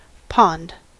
Ääntäminen
IPA : /ˈpɑnd/ US : IPA : [ˈpɑnd]